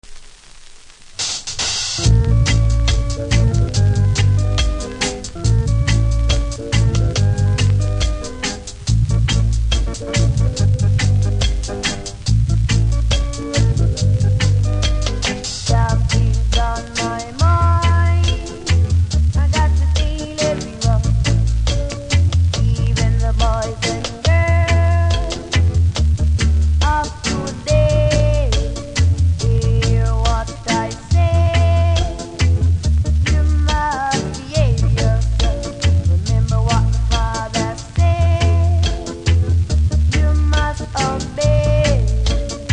REGGAE 70'S
少しプレス起因のヒスあります。